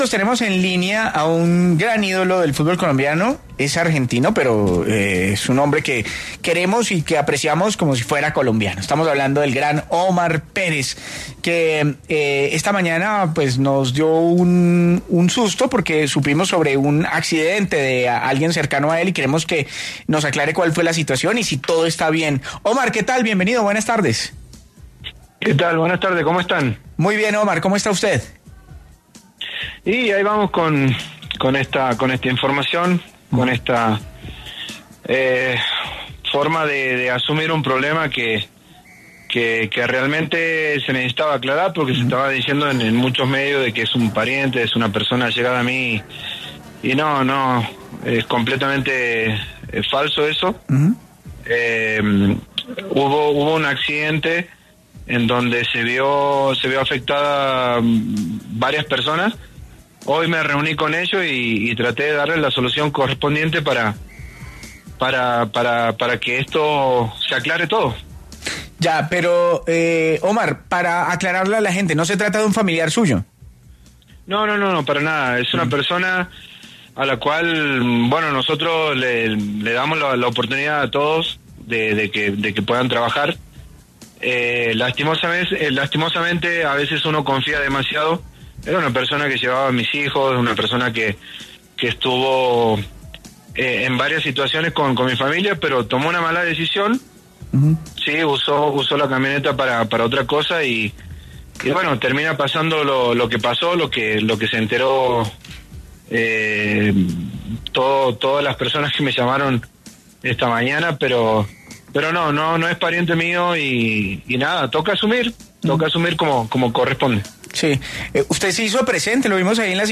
Uno de los máximos referentes en la historia del León esclareció los hechos en exclusiva con el Vbar Caracol.